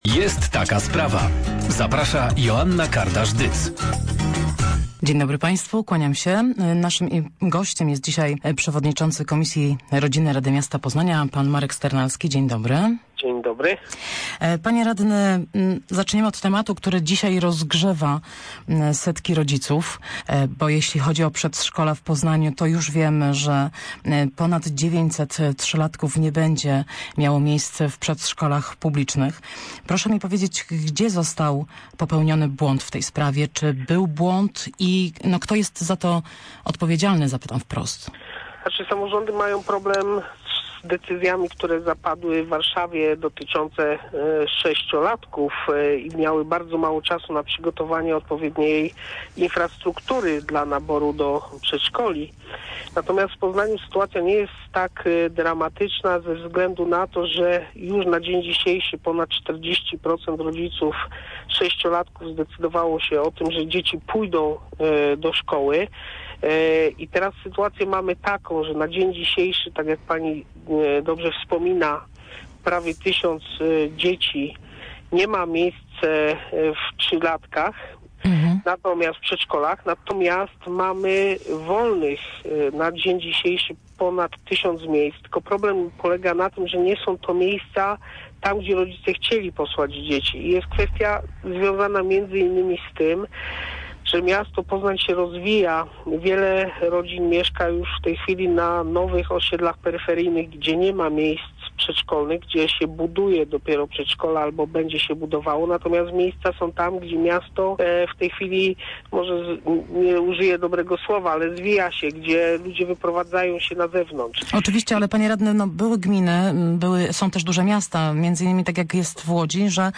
- Nad planem rozwoju miasta pracują radni wszystkich opcji politycznych i kilku komisji -  powiedział w porannej rozmowie Radia Merkury przewodniczący Komisji Rodziny Rady Miasta Poznania Marek Sternalski.
ay78z1zkdmr2c9d_sternalski_rozmowa_przedszkola.mp3